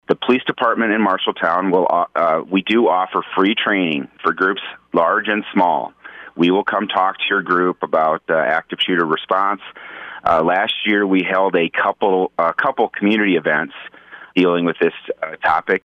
Marshalltown Police Chief Mike Tupper joined the KFJB line to talk about what we can do if it happens here.